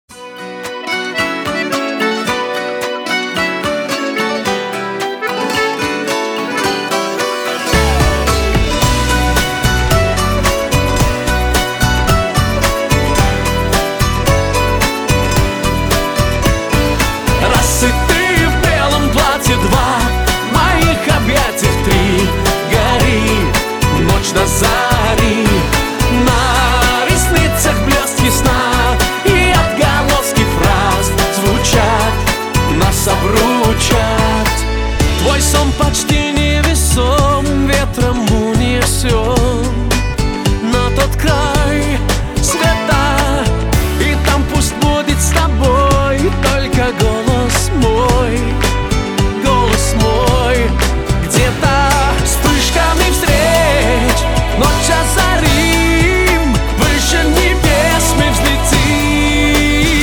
Pop
А его голос - проникновенный и страстный!